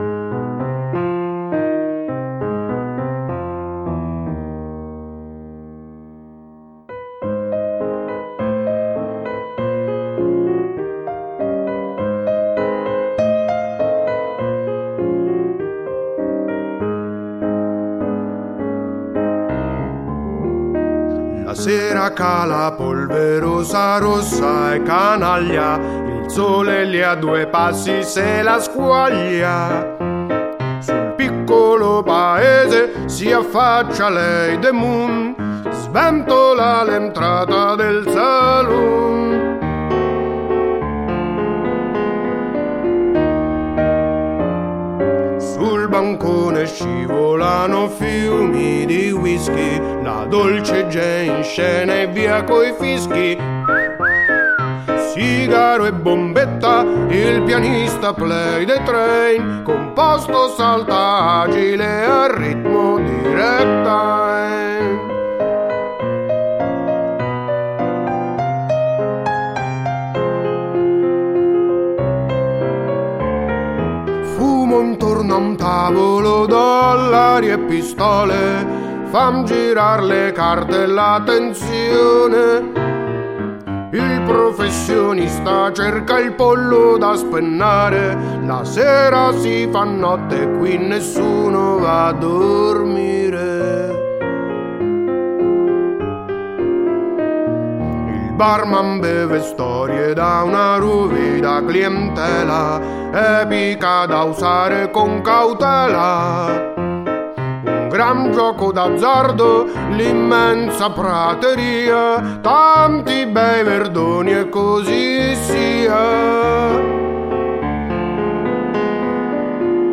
Il Ragtime strumentale è una rielaborazione del primo tema di: The Thriller Rag by May Aufderheide 1909